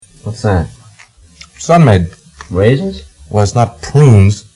It was a play-on-tape, without any sort of clues as to what was occurring.
That was bad enough, but horrible acting made the concept even worse. Of course, that's not yet mentioning the fact that it all sounded like it was recorded in an echoing room with two mics (to give it that stereo "quality").